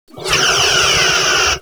OtherPhaser2.wav